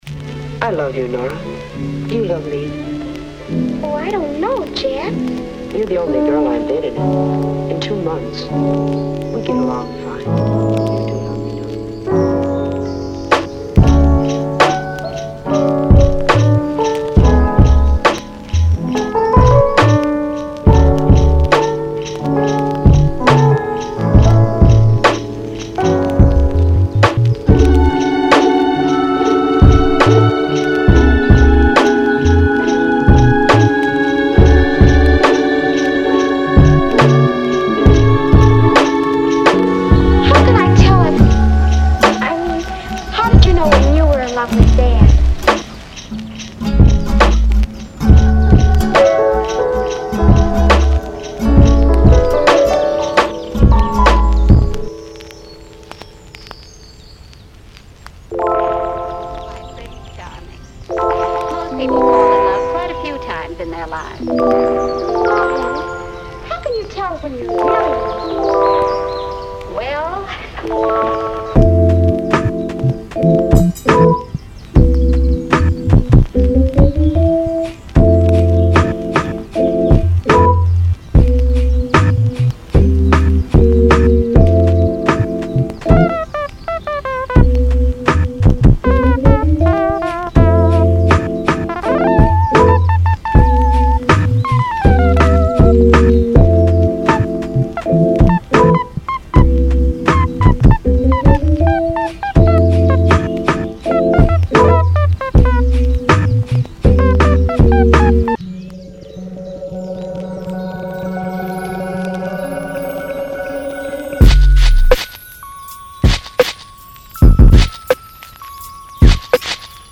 为了为您的Lo-Fi节奏打下坚实的基础，我们提供了大量的老式鼓循环和单拍-结合了出色的低保真效果和
微妙的乙烯基裂纹。
借助我们的5个构建套件，在几分钟之内创建出色的节拍-您可以在演示轨道上听到所有这些!
.010x-(Electric Guitar Loops)
.005x-(Electric Piano Loops)
.005x-(Emotional Piano Loops)
.040x-(Melodic Synth Loops)
.008x-(Vinyl Crackles)
.002x-(Wind Chimes)